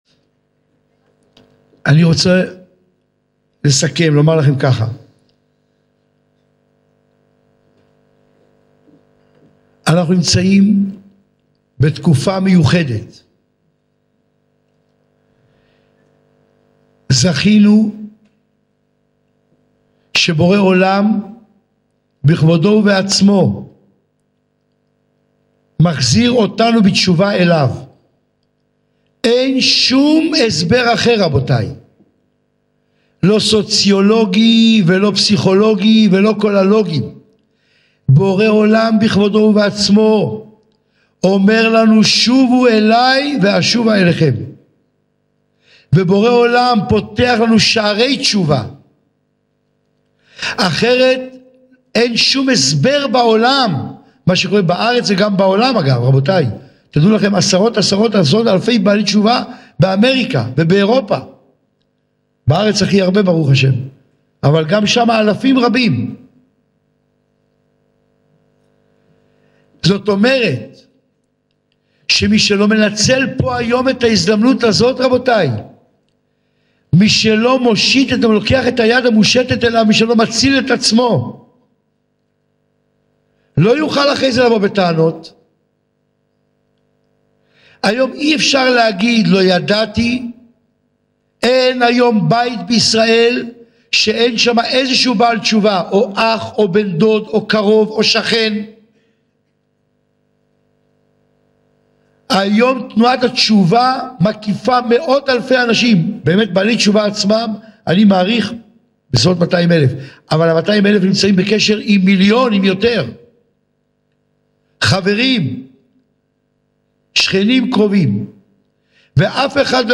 שעור תורה לזיכוי הרבים